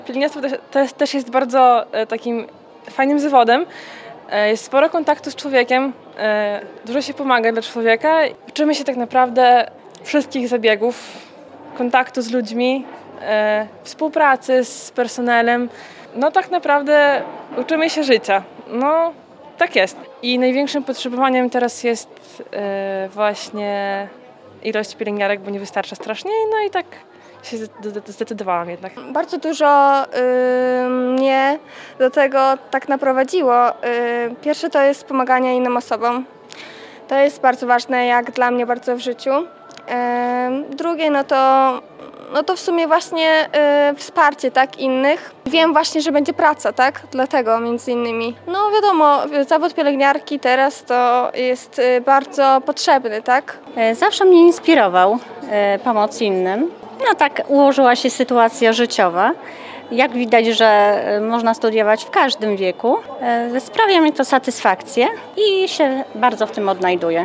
Jak mówili studenci, są powołani do pomocy drugiemu człowiekowi.